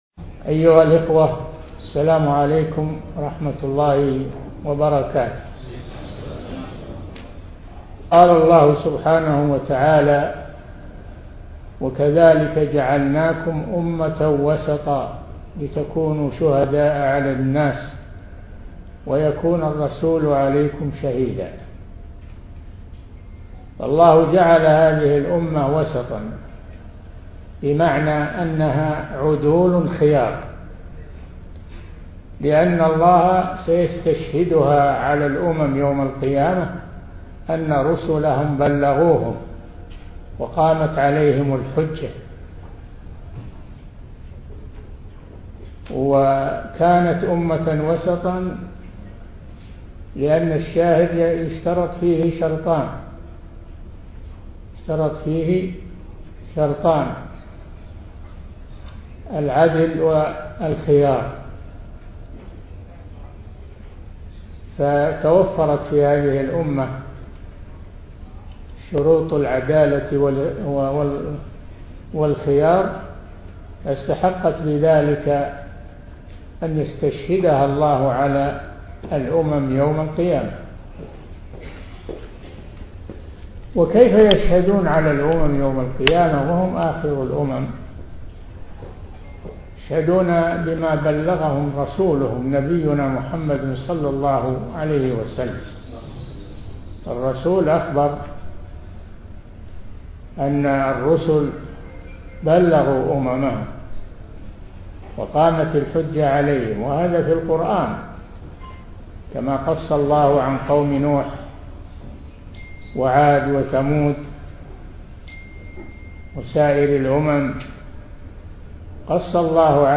شبكة المعرفة الإسلامية | الدروس | وسطية الإسلام ومحاربة التطرف |صالح بن فوزان الفوزان